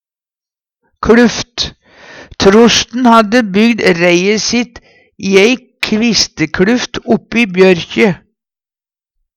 ei kluft - Numedalsmål (en-US)
kLuft.mp3